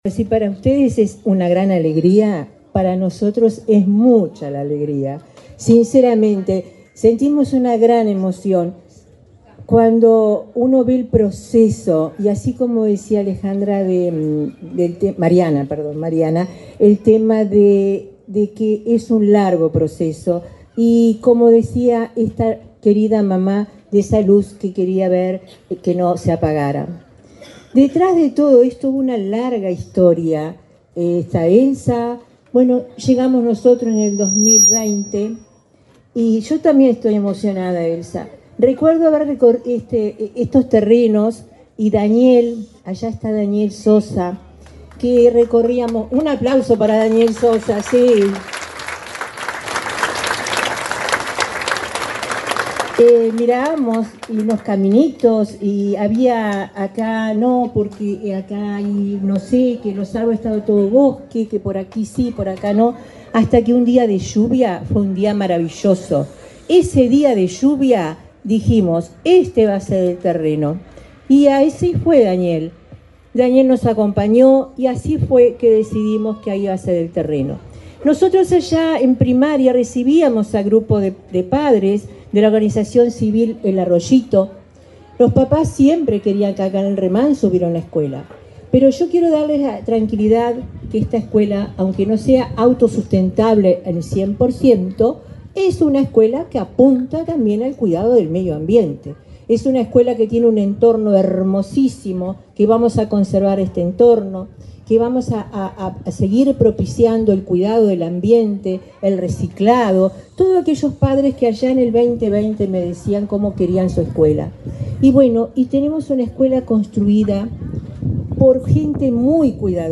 Palabras de autoridades de la ANEP
Palabras de autoridades de la ANEP 22/10/2024 Compartir Facebook X Copiar enlace WhatsApp LinkedIn La directora general de Educación Inicial y Primaria, Olga de las Heras, y la presidenta de la Administración Nacional de Educación Pública (ANEP), Virginia Cáceres, participaron en la inauguración de la escuela n.° 318, de doble turno, de Remanso de Neptunia, departamento de Canelones.